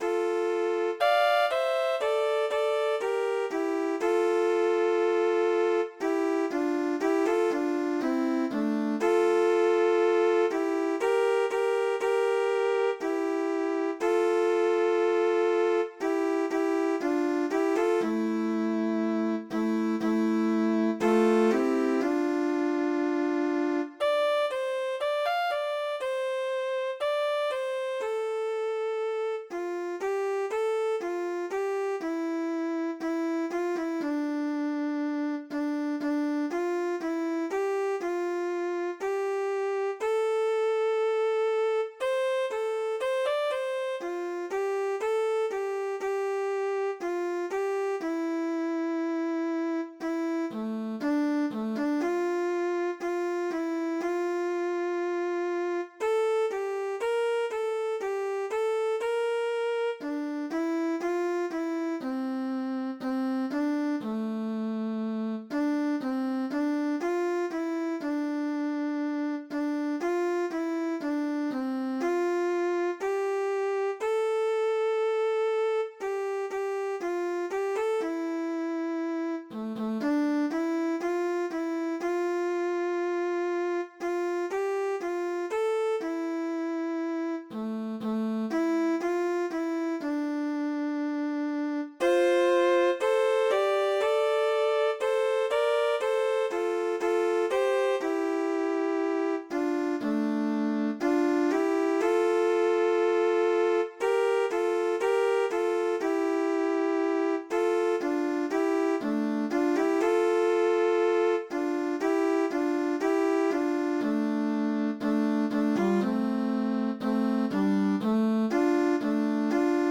2 bè